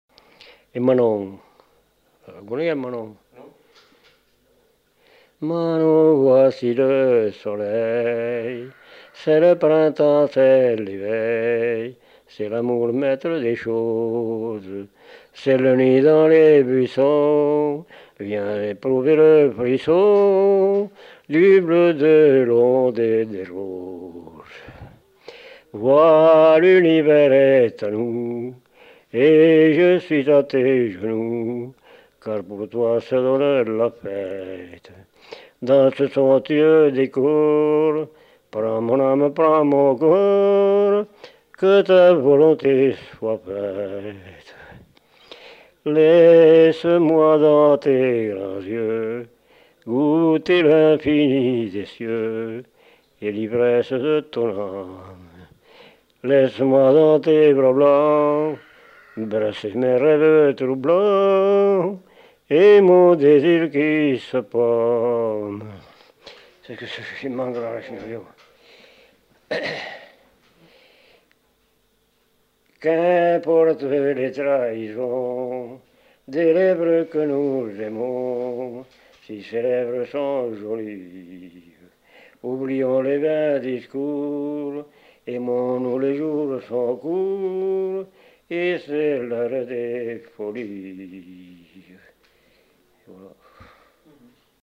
Aire culturelle : Lomagne
Lieu : Faudoas
Genre : chant
Effectif : 1
Type de voix : voix d'homme
Production du son : chanté